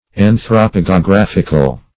An`thro*po*ge`o*graph"ic*al, a.